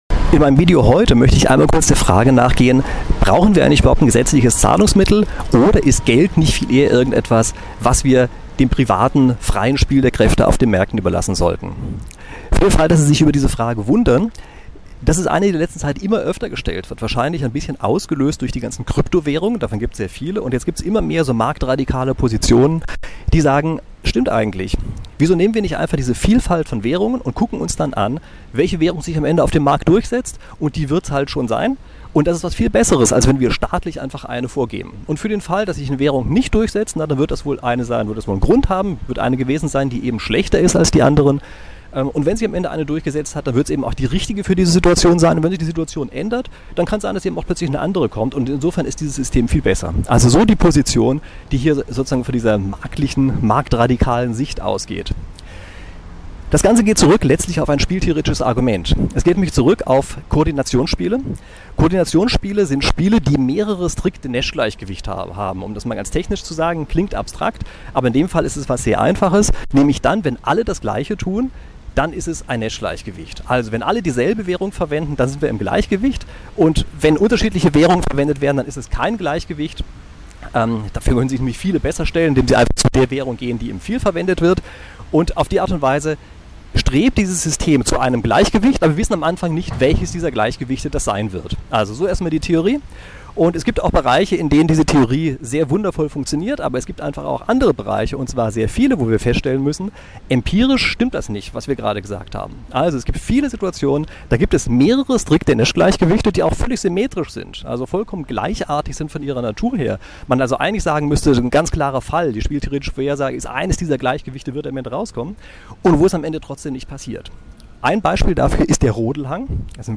Sorry für den schlechten Ton: Vor dem Eurozeichen in Frankfurt
ist ein Höllenlärm